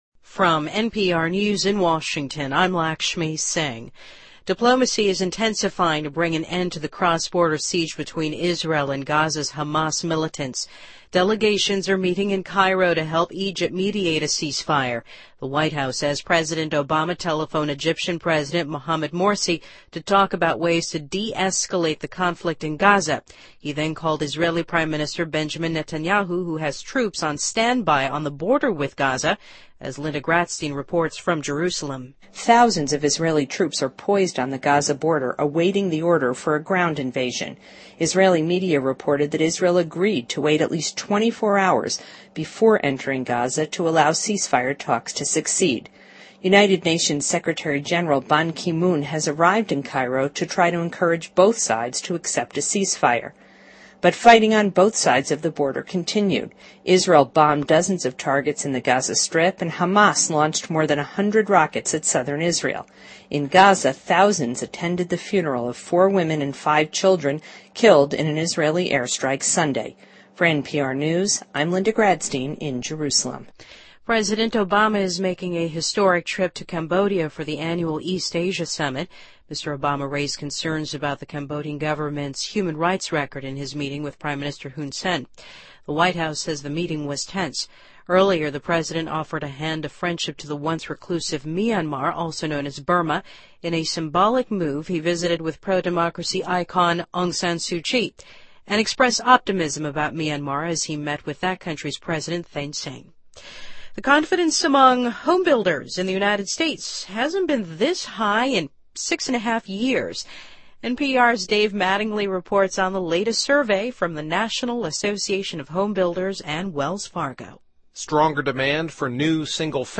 NPR News,2012-11-20